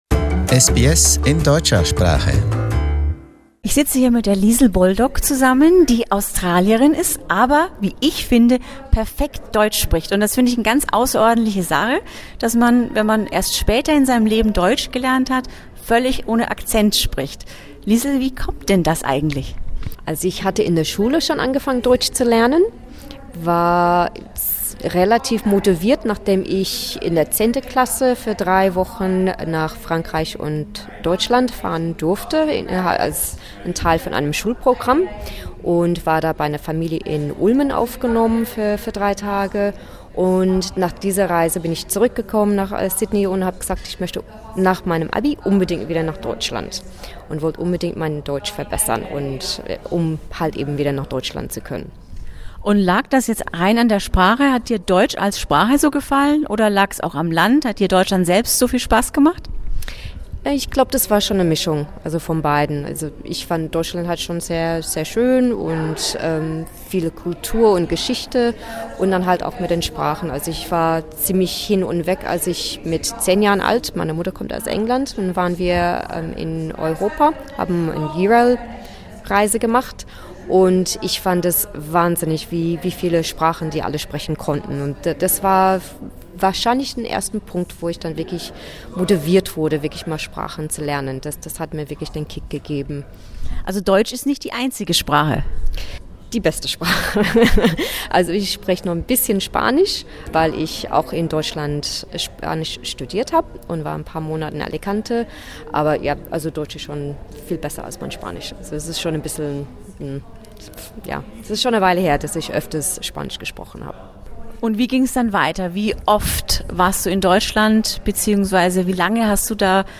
SBS German